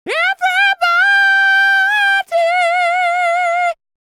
DD FALSET043.wav